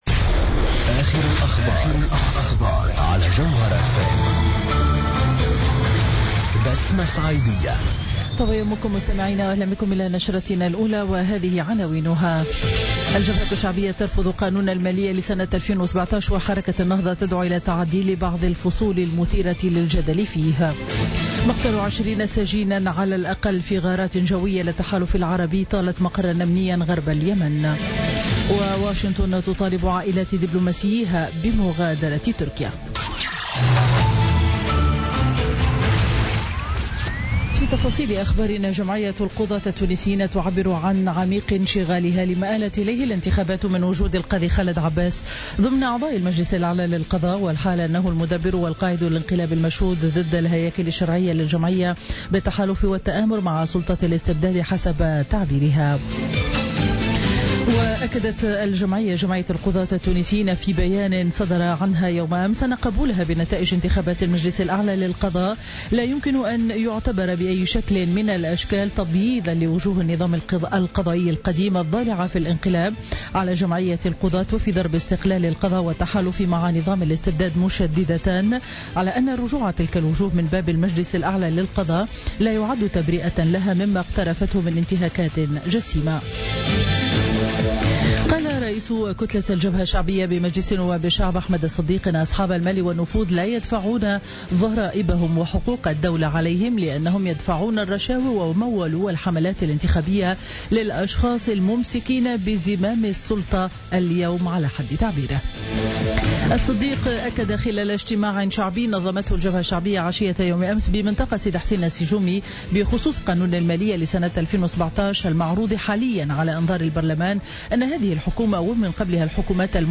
Journal Info 07h00 du dimanche 30 octobre 2016